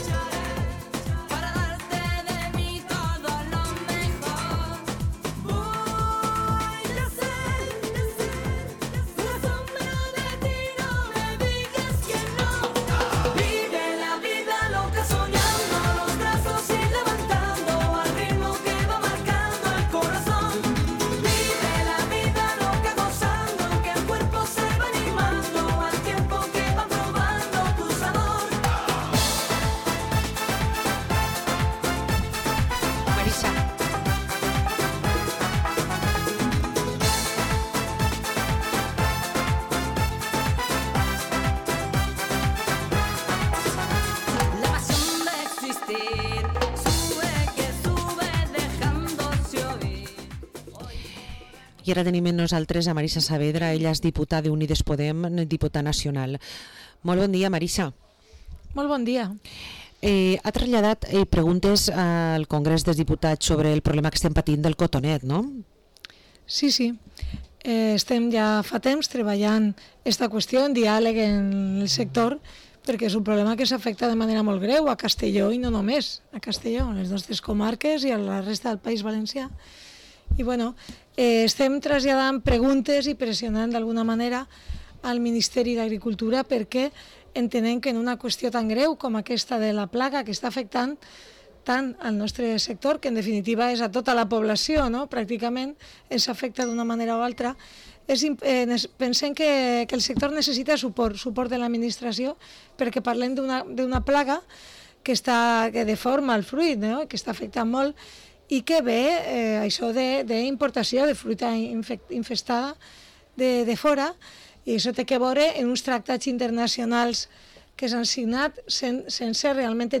Entrevista a la Diputada Nacional de Unidas Podemos, Marisa Saavedra